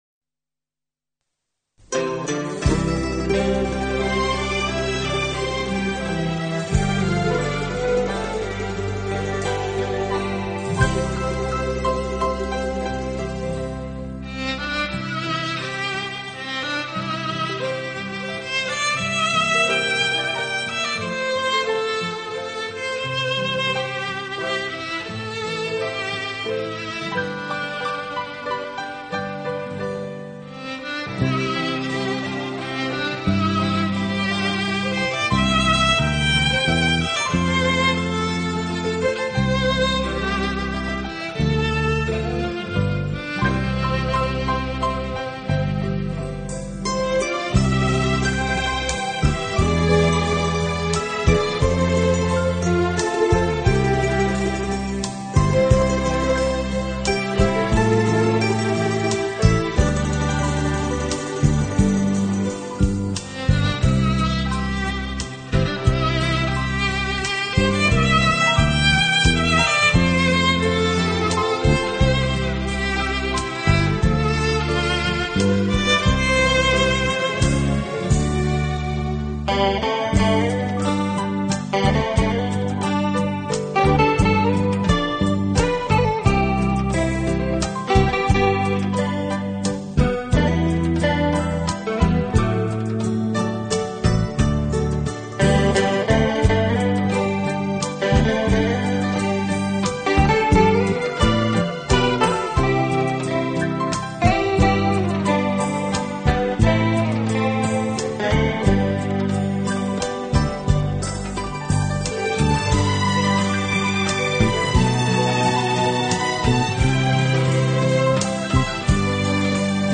浪漫纯音乐
经典歌曲等，经不同乐器演奏，谱写出一个个浪漫的诗